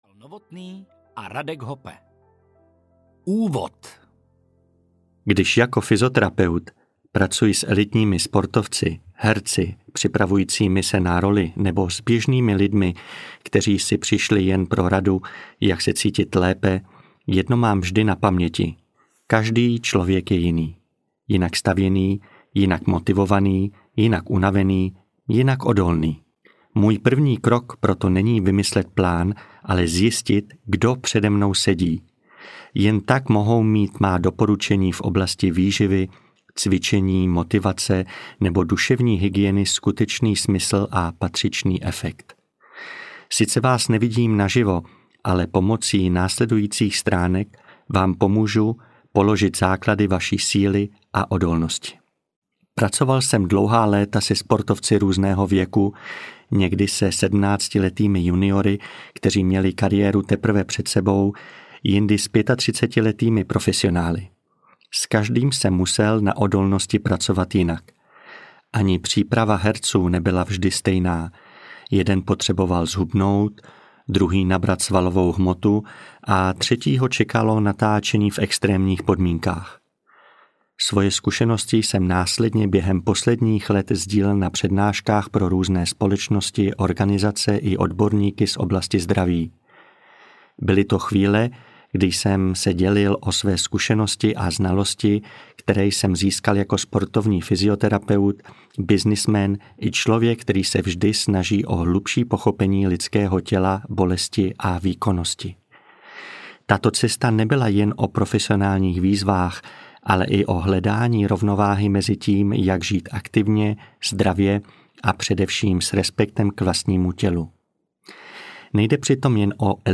Za hranice odolnosti audiokniha
Ukázka z knihy
za-hranice-odolnosti-audiokniha